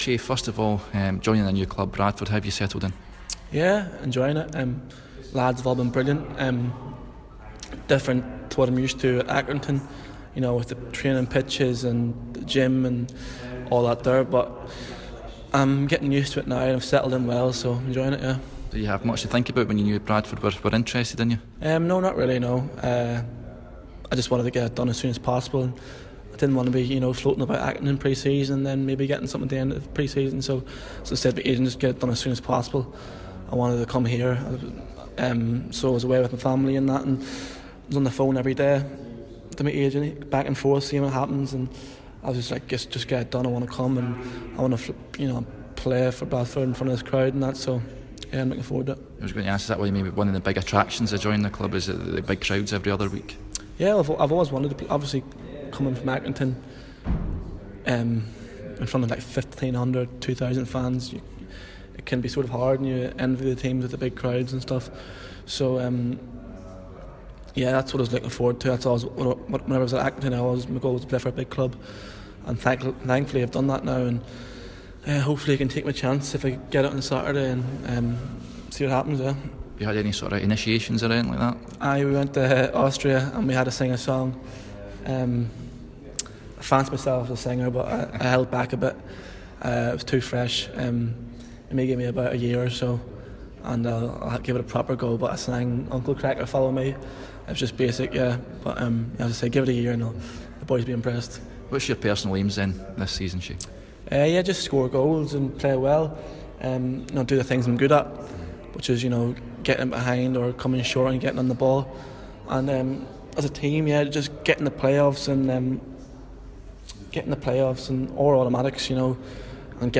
New Bradford City signing Shay McCartan speaks to Radio Yorkshire ahead of the 2017/18 season